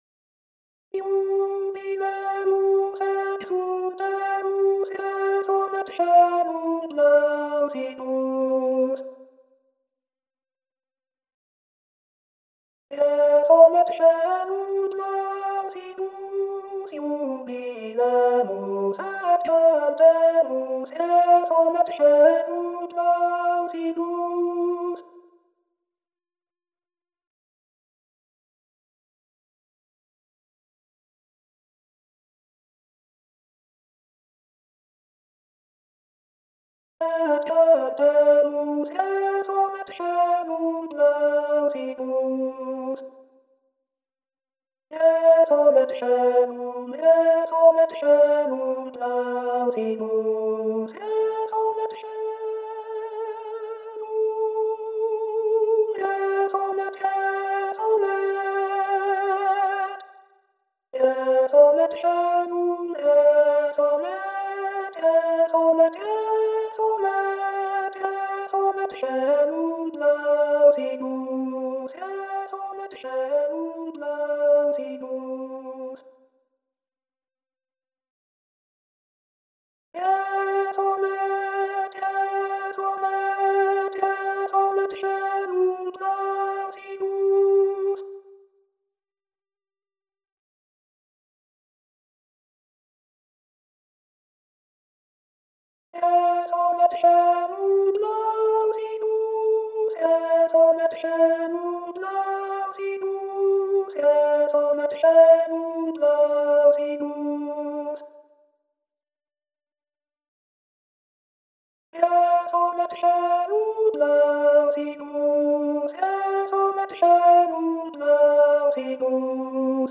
Couperin Jubilemus.alt.mp3